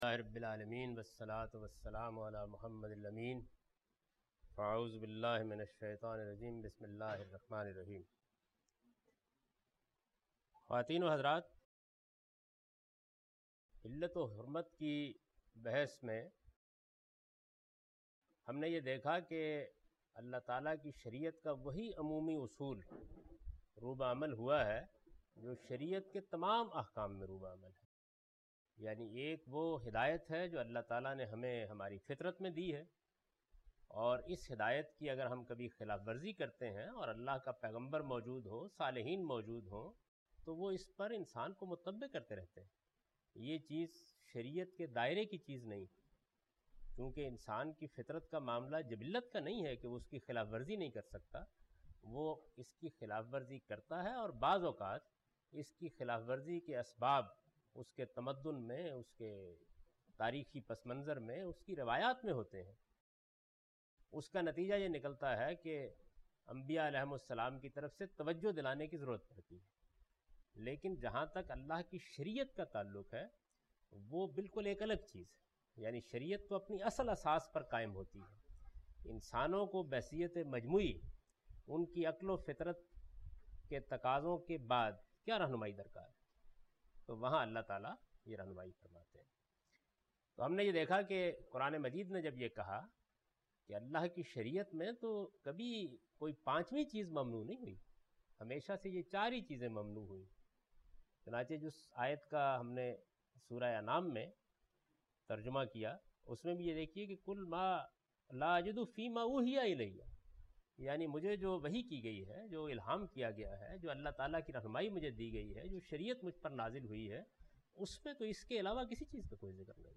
A comprehensive course on Islam, wherein Javed Ahmad Ghamidi teaches his book ‘Meezan’.
In this lecture he teaches the the ruling of Hadith in order to interpret and understand the Quran. (Lecture no.34– Recorded on 10th May 2002)